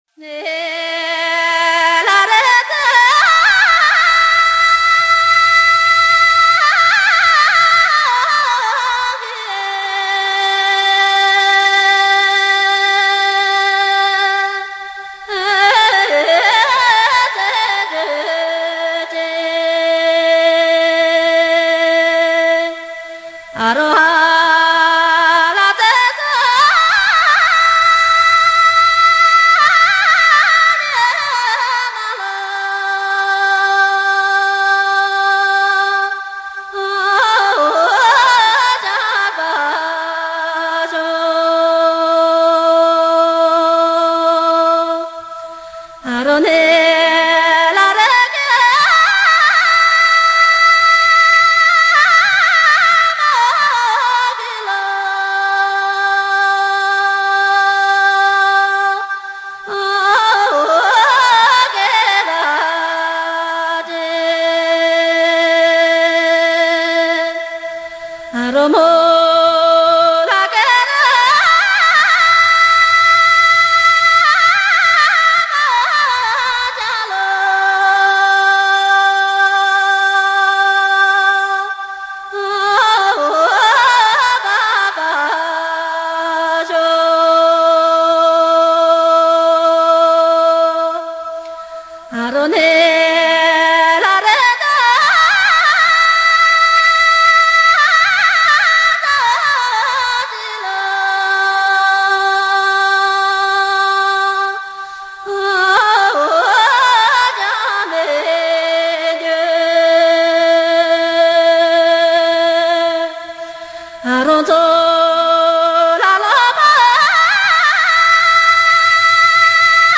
[31/7/2011]藏北 那曲 最原生态 一支牧歌